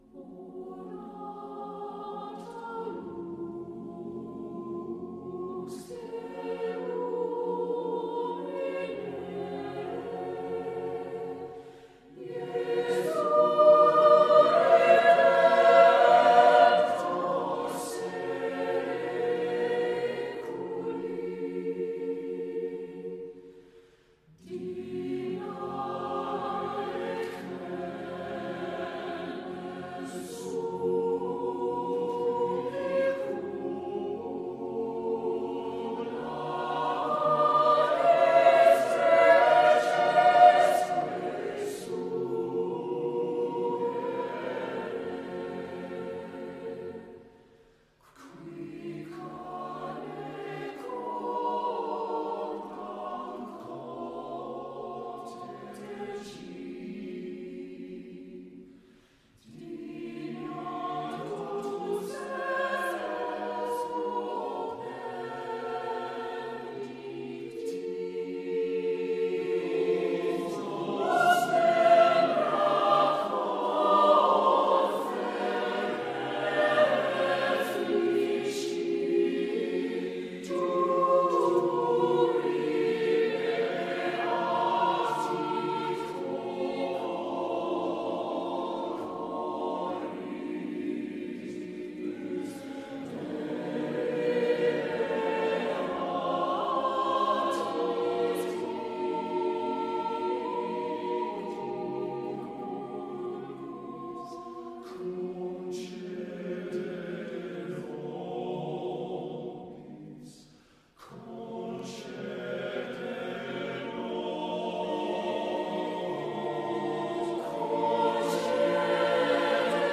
Voicing: "SSAATTBB"